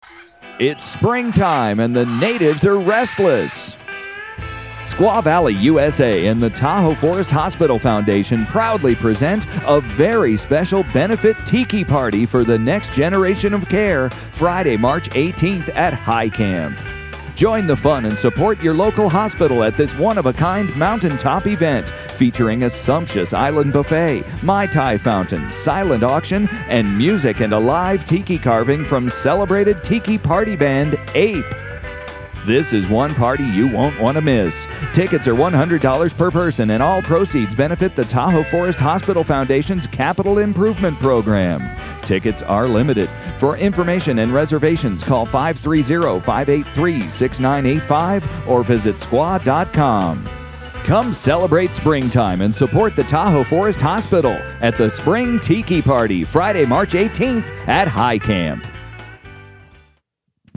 info-mercial here: